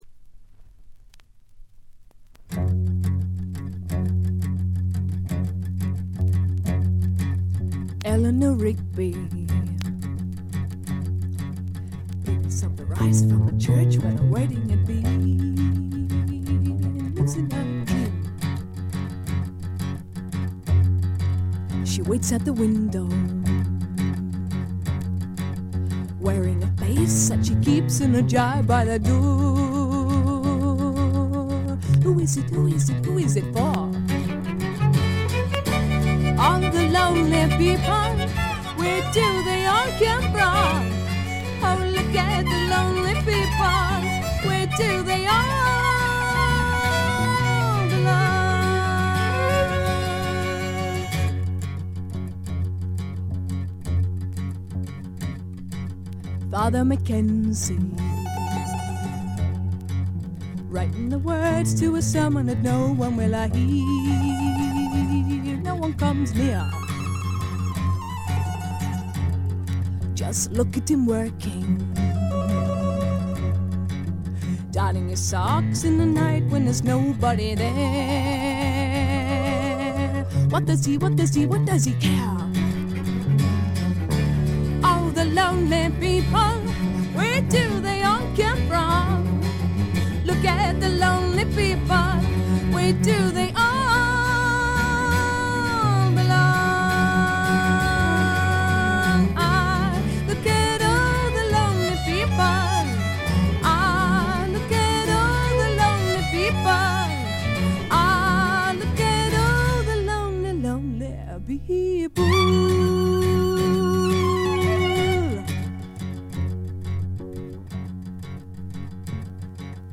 特にB1序盤の3連のプツ音は少し目立ちますが、他はまずまず。
試聴曲は現品からの取り込み音源です。